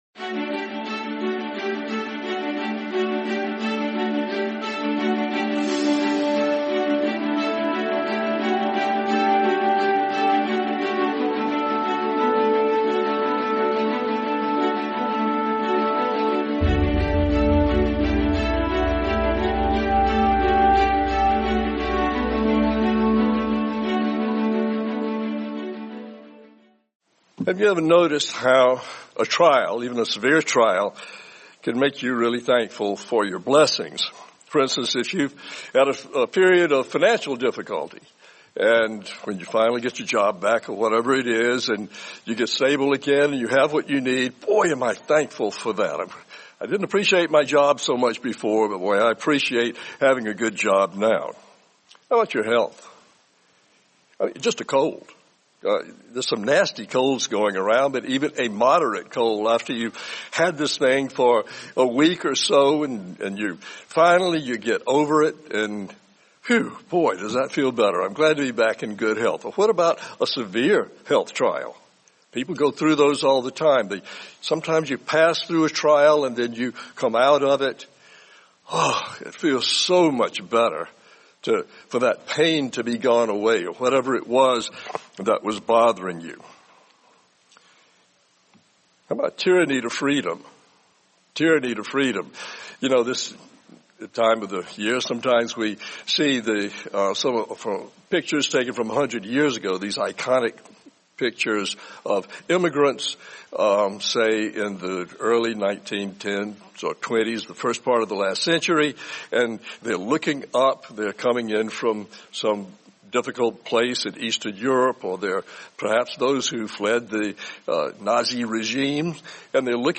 Sermon Thanksgiving Year 'Round